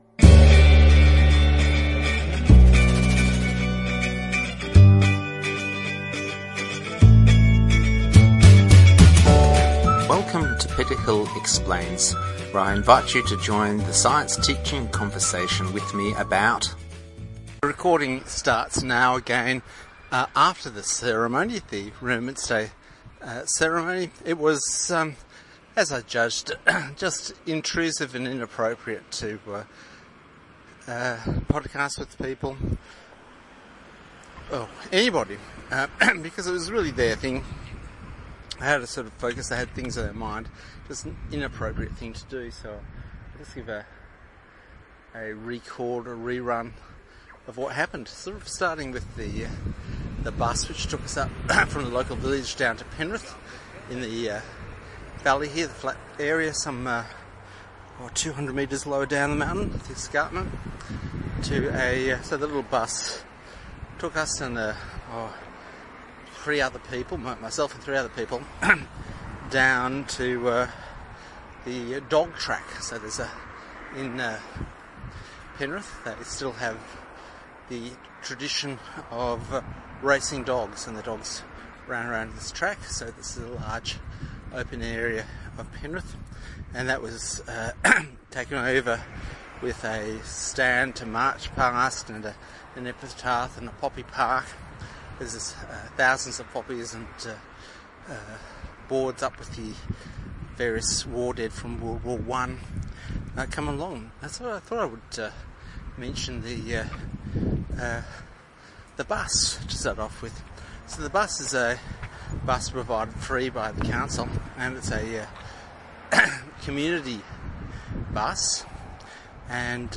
You might want to listen as I walk to a shuttle bus and promise interviews with key figures in this remembrance day march.
I walked through the streets of Penrith and you can sense the sound scape of traffic and the audible traffic lights as I make my way to the station. I continue for a few minutes whilst on the platform.
On arrival in Glenbrook we are greeted by the Mountain cicadas that generally are a little harder for the kookaburras to catch. I finish on reflection that remembrance is a collective creation which conventions slowly burning in as people slowly pass away.